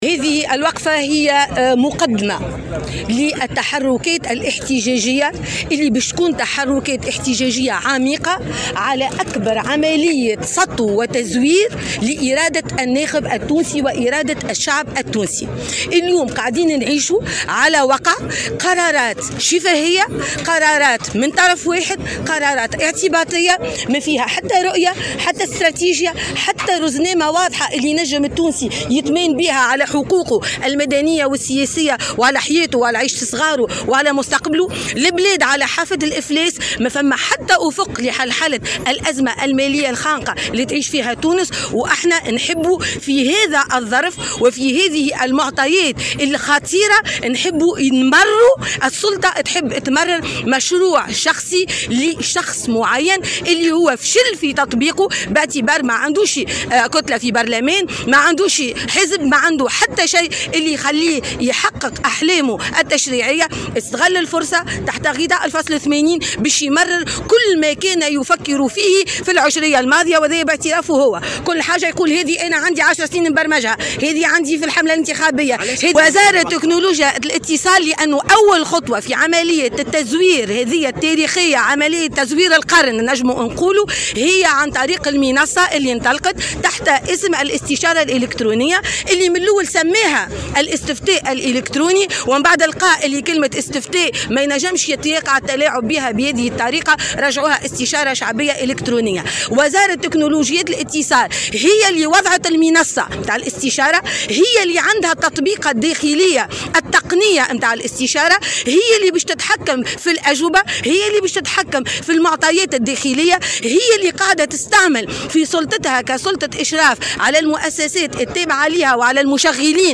وأعلنت موسي في تصريح لمراسل الجوهرة أف أم، خلال وقفة احتجاجية للدستوري الحر ضد الاستشارة الوطنية أمام وزارة تكنولوجيات الاتصال بالعاصمة، عن رفع قضية ضد هذه الوزارة تعهدت بها فرقة الشرطة العدلية بالمنزه، بعد طلب نفاذ للمعلومة تقدم به الحزب من أجل التعرف على السند القانوني وهوية الأشخاص الذين كلفوا بإعداد الأسئلة الواردة ضمن هذه الاستشارة.